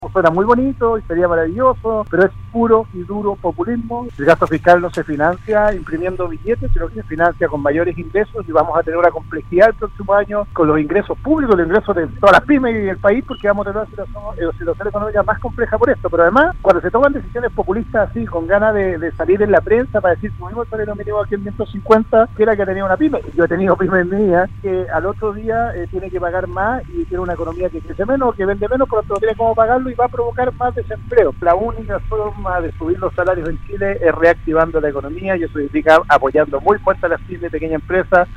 En conversación con Radio Sago, el ministro de Desarrollo Social, Sebastián Sichel, conversó sobre la contingencia social que vive el país y de cómo se busca, mediante los diálogos ciudadanos una alternativa para solucionar la crisis.